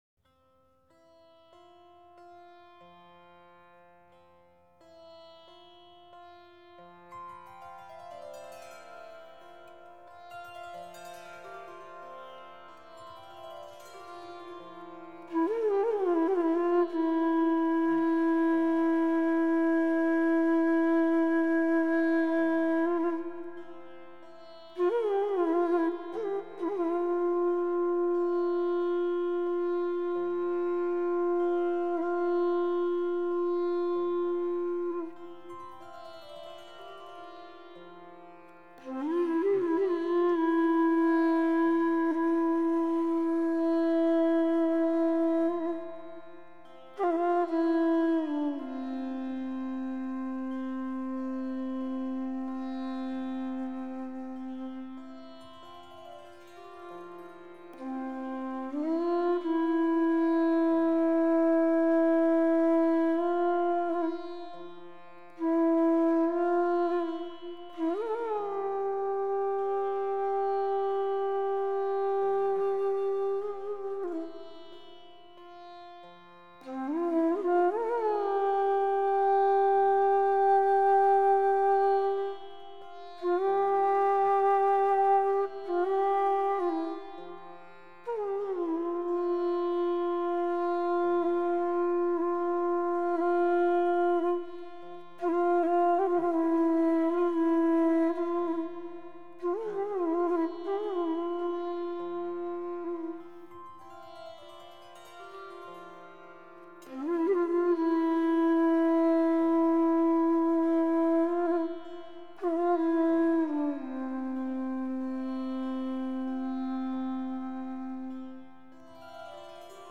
Beispiel: Raga f�r die Zeit von 10-13 Uhr